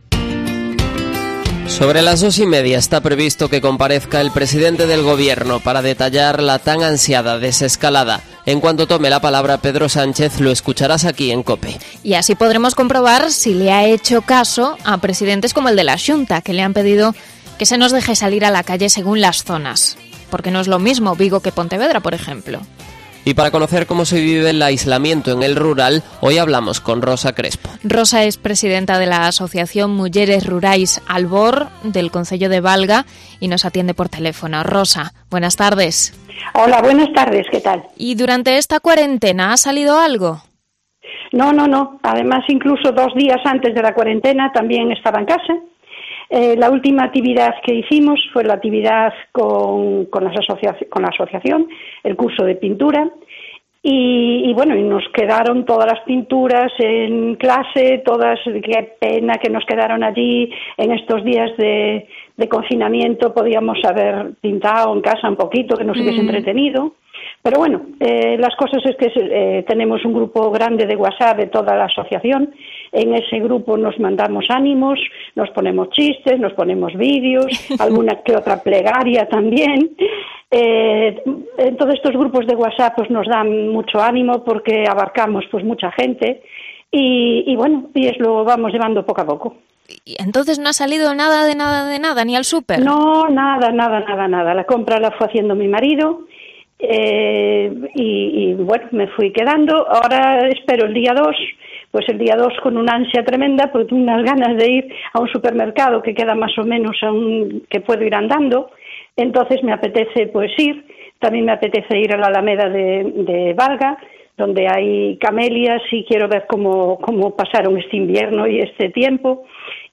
Entrevista a una vecina de Valga que vive el confinamiento en el rural